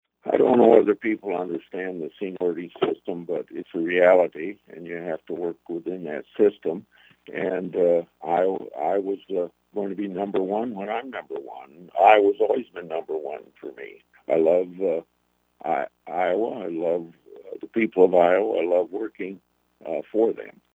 Grassley made his comments on October 18th during an interview with Iowa radio reporters.